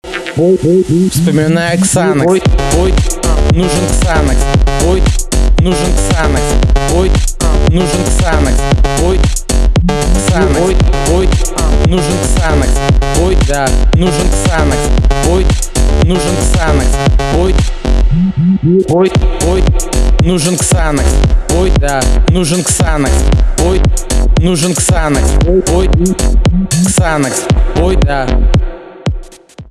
русский рэп , битовые , басы , качающие